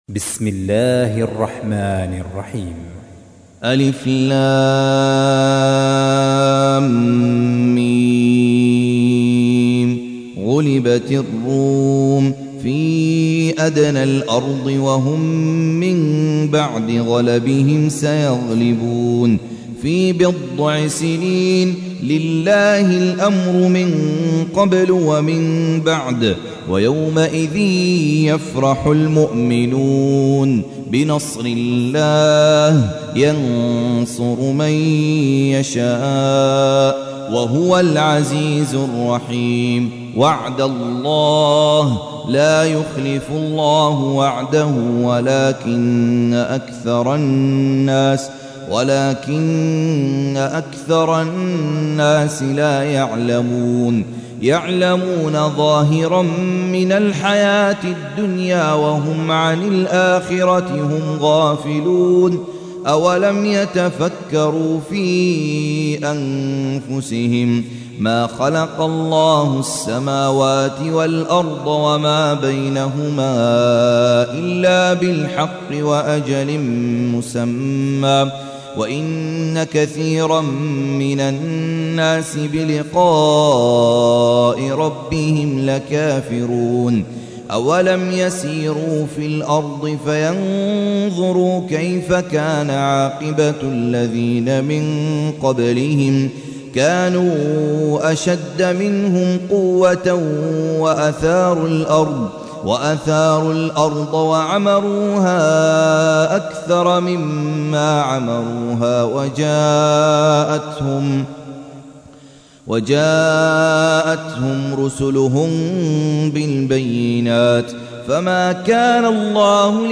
تحميل : 30. سورة الروم / القارئ خالد عبد الكافي / القرآن الكريم / موقع يا حسين